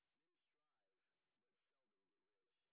sp06_white_snr30.wav